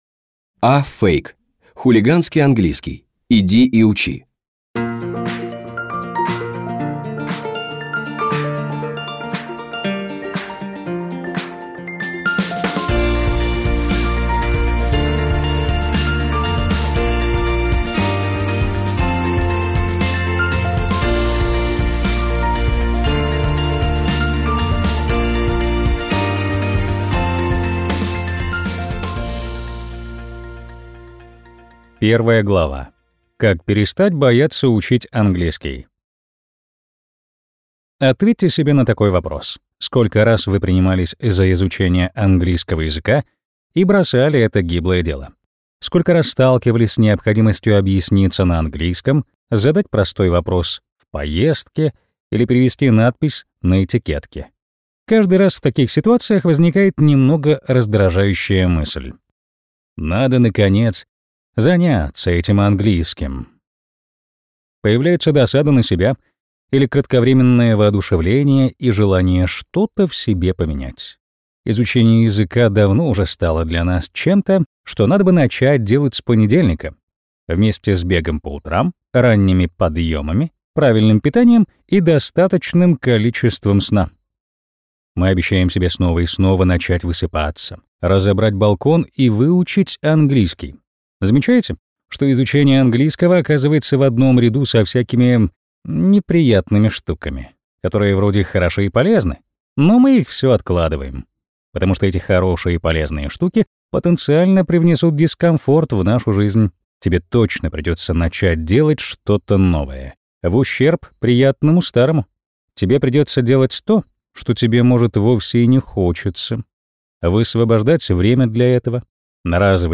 Аудиокнига Хулиганский английский. Иди и учи | Библиотека аудиокниг